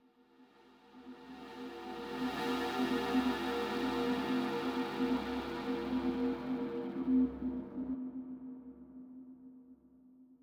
ajout du sfx d'ascenseur
elevator.wav